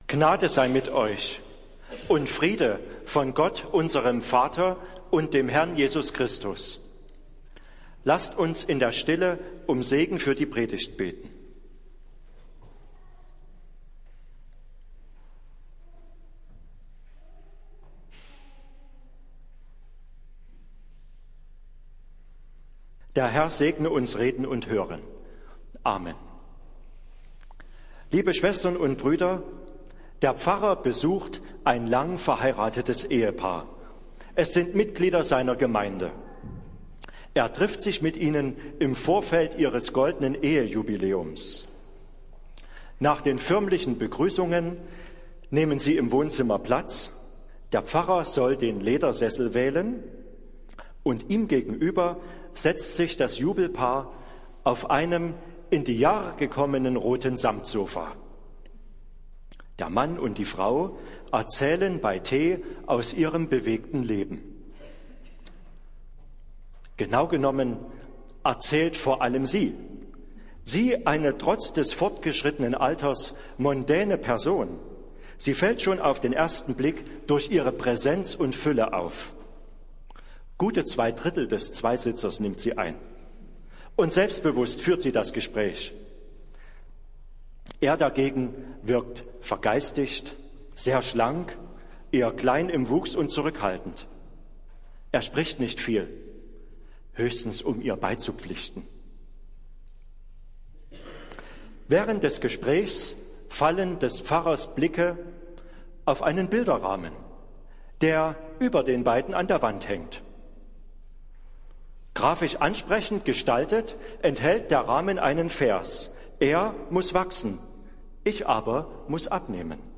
Predigt des Gottesdienstes aus der Zionskirche am Sonntag, den 25. Juni 2023